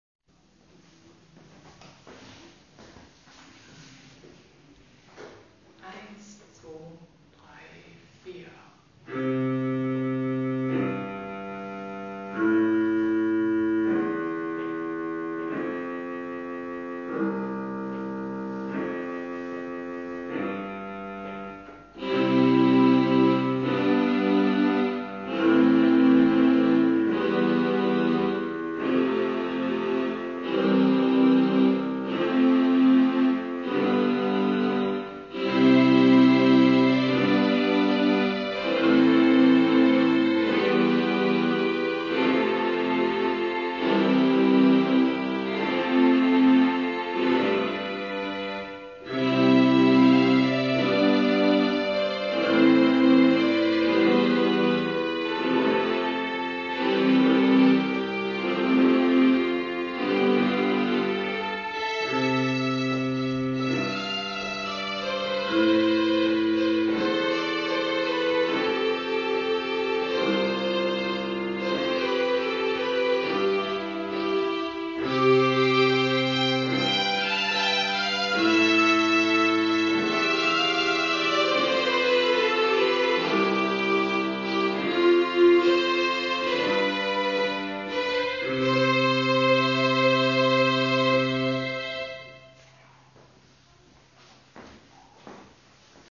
Pachelbel_Keyboard_AG.mp3